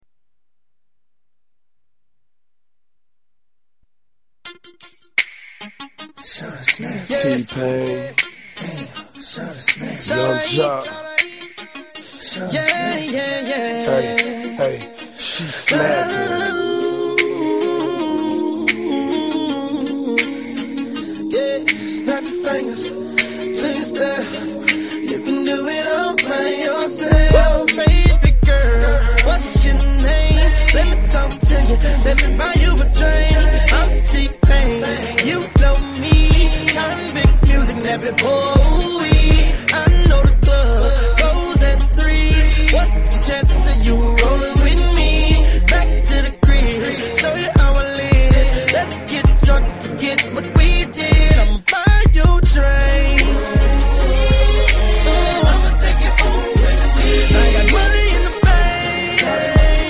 在一份电子杂志上听到的歌，觉得很独特，但是效果不太好。